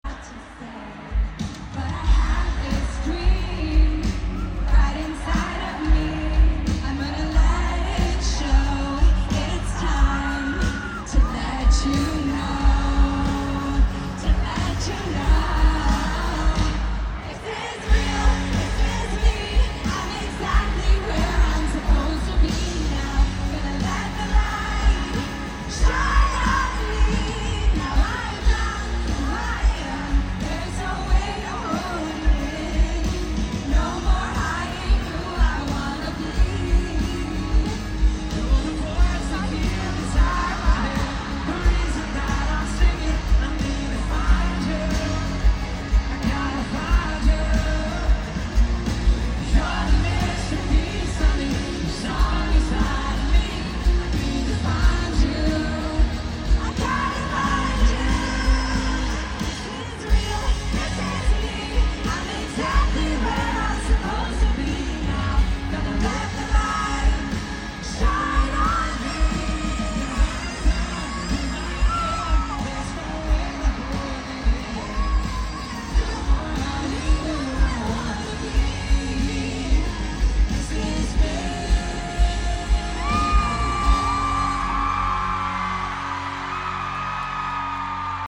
at opening night